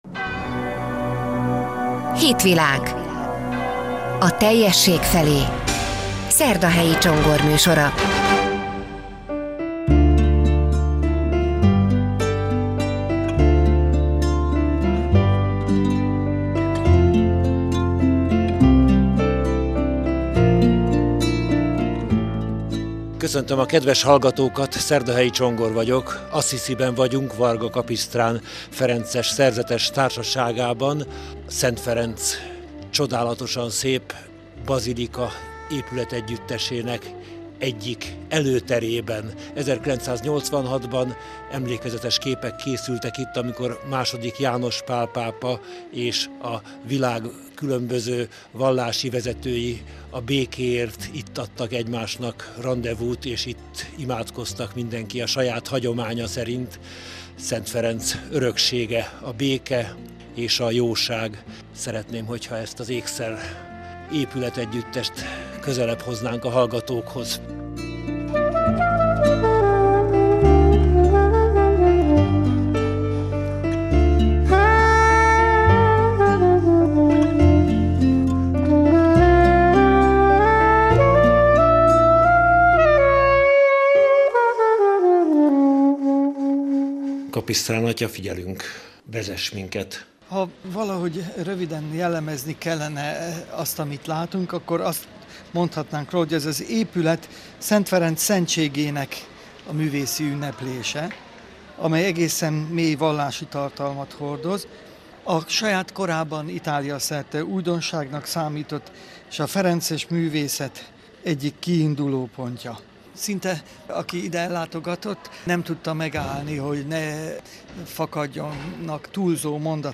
Assisiben, a Szent Ferenc-bazilika előtti zarándokudvarban és az alsó templomban nézhetünk körül a Lánchíd Rádió jóvoltából. A Hitvilág július 26-i műsorát itt meghallgathatja.